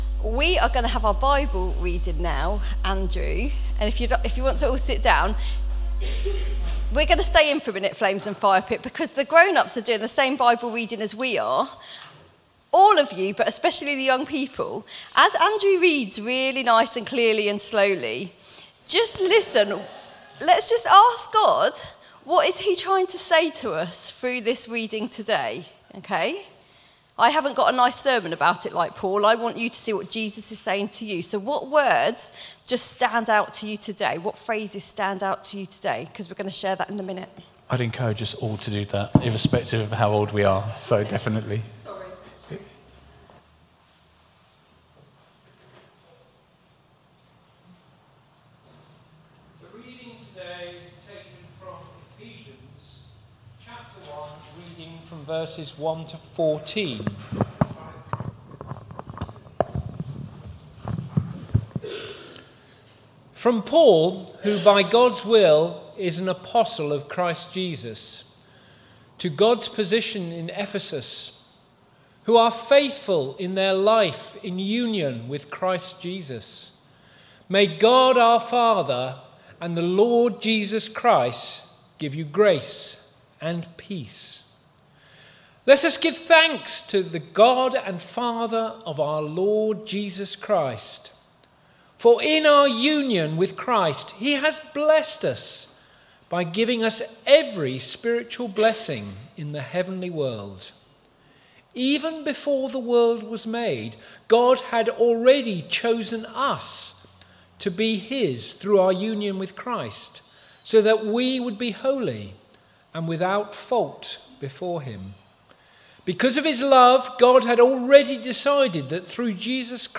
Sermon 8th Oct 2023 11am gathering
We have recorded our talk in case you missed it or want to listen again.
SUNDAY 8th October 2023 11am Gathering